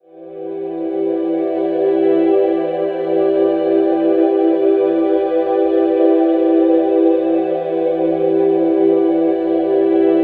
PENSIVE   -L.wav